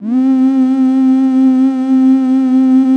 14PAD 01  -L.wav